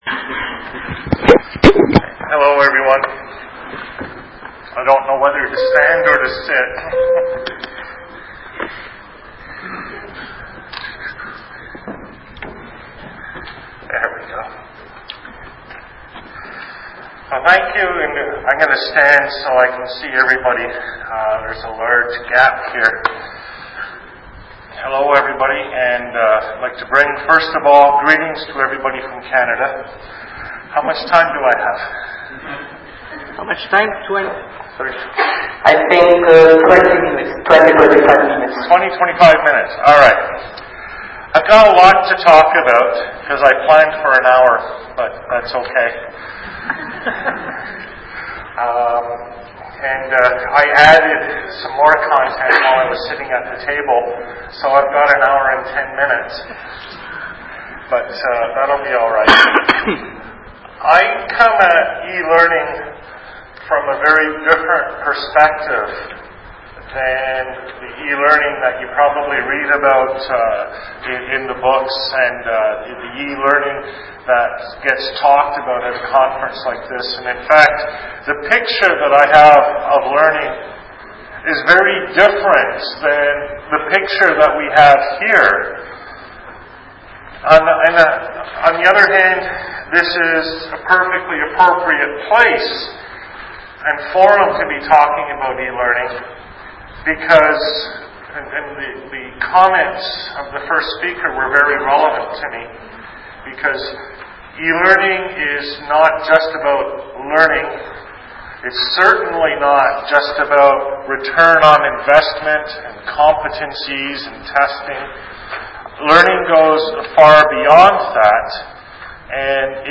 Talk at the opening of the International Conference on Methods and Technologies for Learning at the Palazzo dei Normanni, Palermo, Sicily.